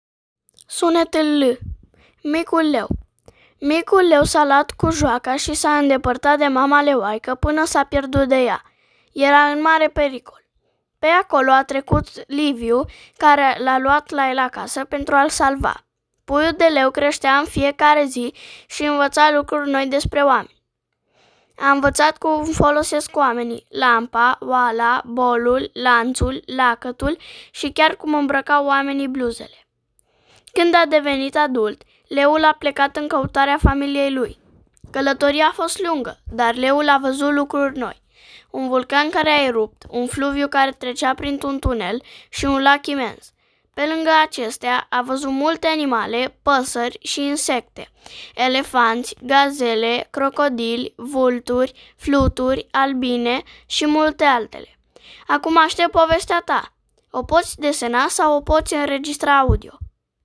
• Descriere: Exersarea sunetului „L” într-o povestire scurtă, care urmărește secvențial aventura unui pui de leu rătăcit.